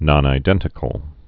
(nŏnī-dĕntĭ-kəl)